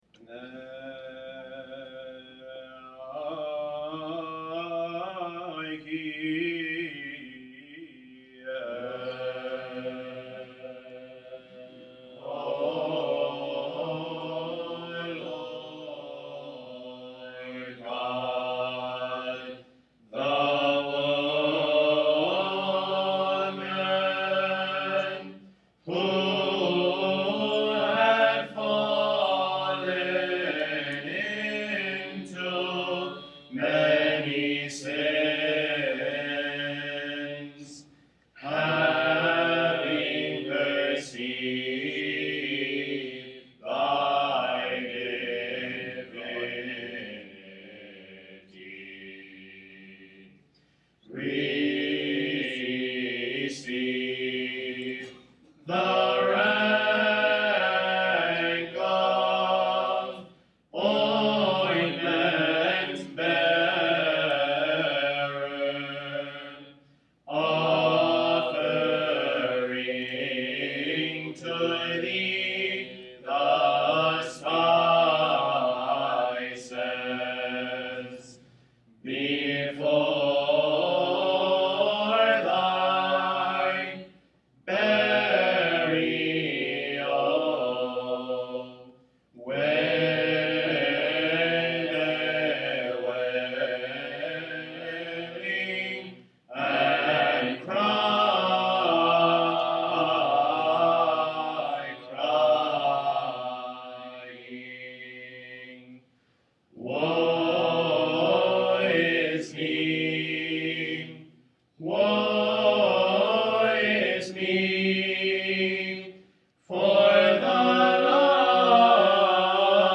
Hymnology Lecture - Sacred Music Institute 2010
Someone in the audience, however, recorded the session, so the Hymn of Kassiane is provided